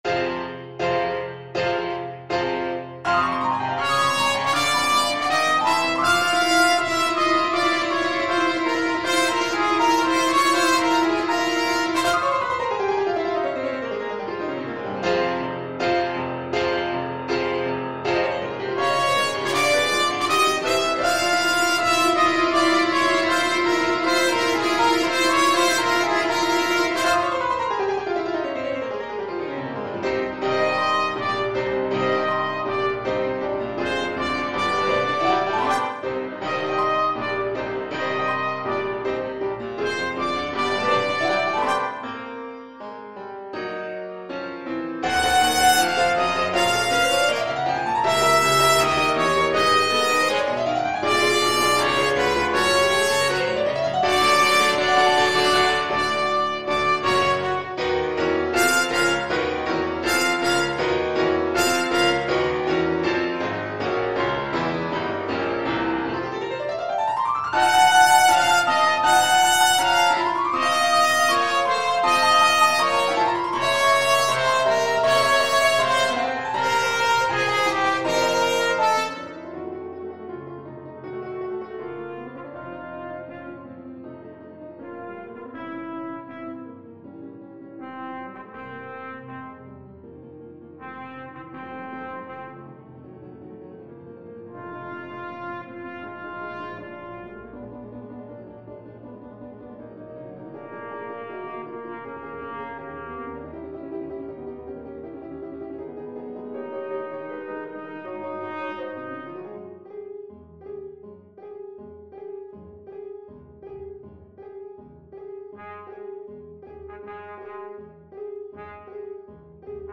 Trumpet
4/4 (View more 4/4 Music)
Allegro agitato (=80) (View more music marked Allegro)
F minor (Sounding Pitch) G minor (Trumpet in Bb) (View more F minor Music for Trumpet )
Ab4-G6
Classical (View more Classical Trumpet Music)
verdi_requiem_dies_irae_TPT.mp3